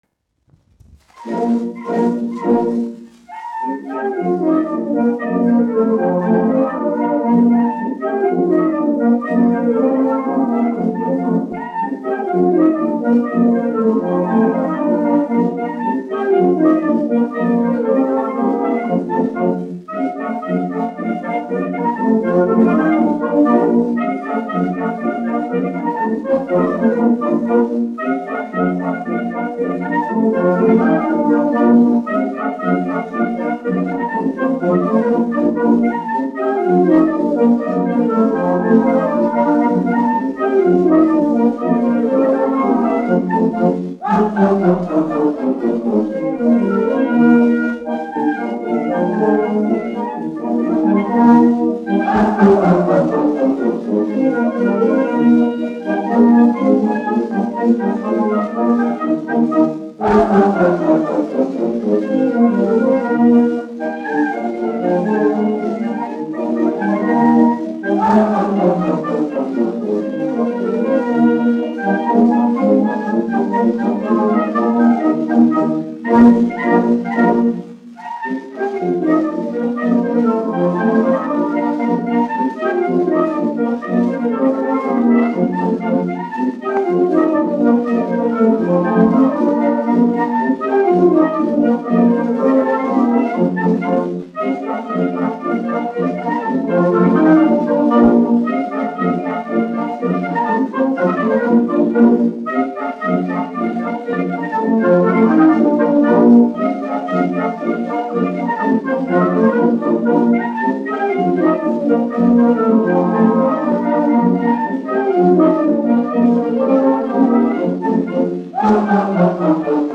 Latvijas 4. Valmieras kājnieku pulka orķestris, izpildītājs
1 skpl. : analogs, 78 apgr/min, mono ; 25 cm
Polkas
Pūtēju orķestra mūzika
Skaņuplate
Latvijas vēsturiskie šellaka skaņuplašu ieraksti (Kolekcija)